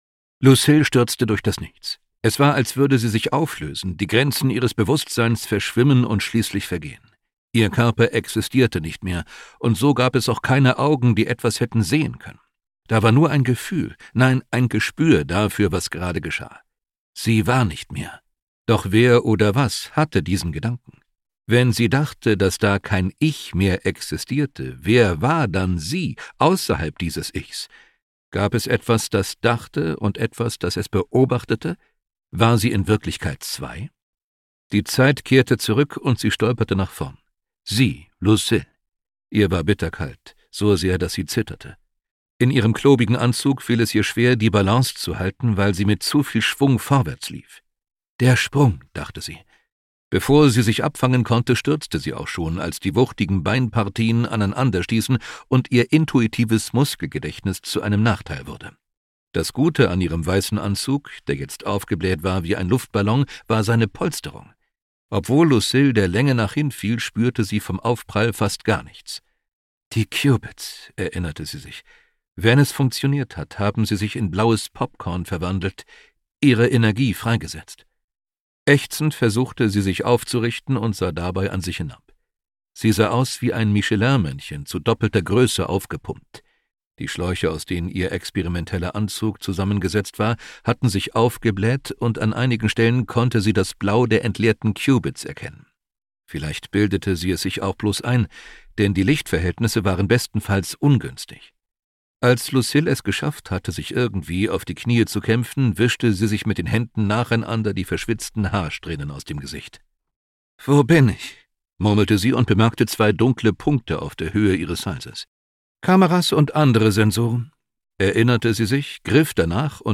Ungekürzte Lesung